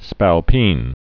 (spăl-pēn)